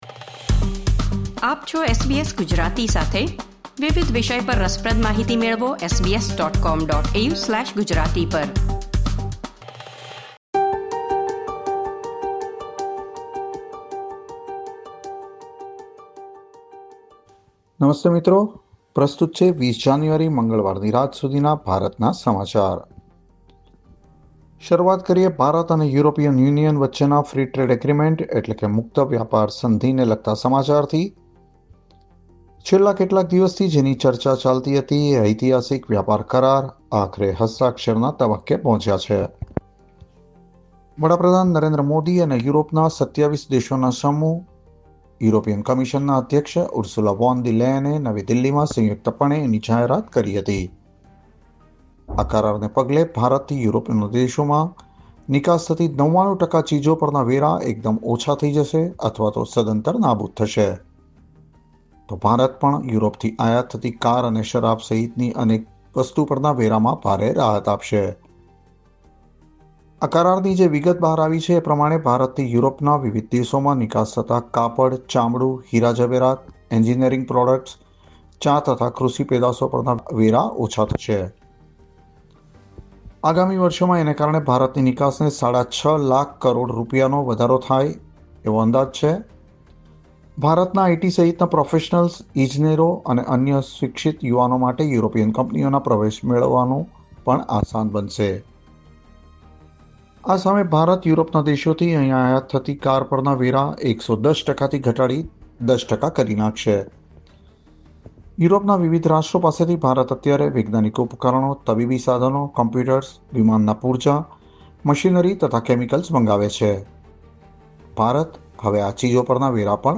Listen to the latest Indian news from SBS Gujarati.